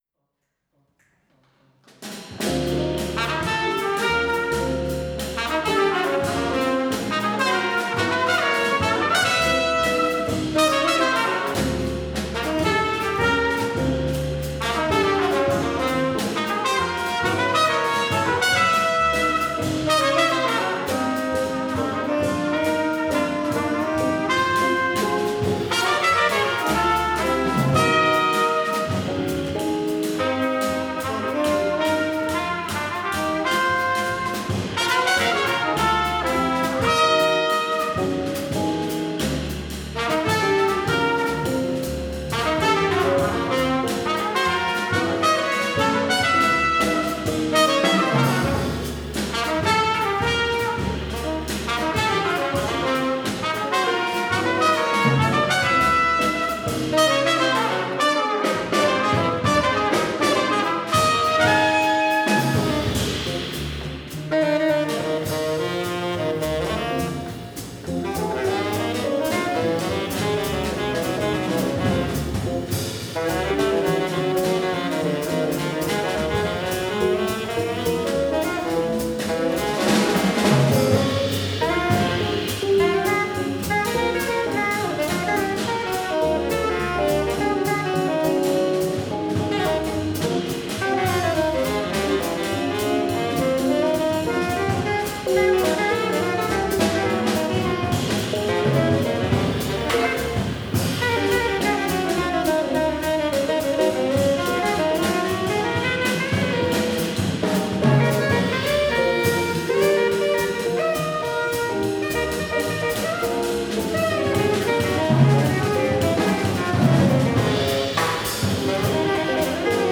JAZZ_110105_koncert_pedagogů jazz_110105_koncert_pedagogu/10